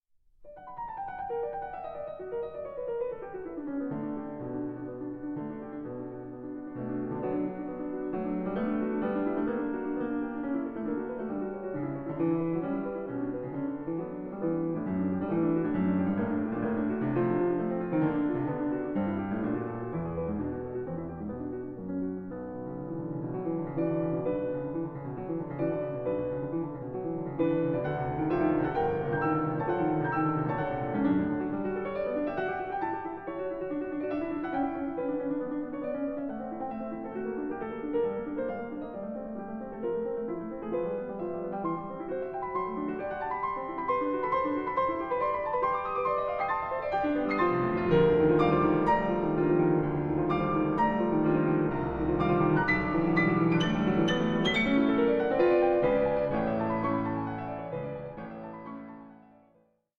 Allegro con brio 6:55
and Strings bursts with irony and theatrical flair